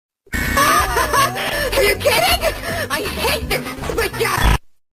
laugh sound effect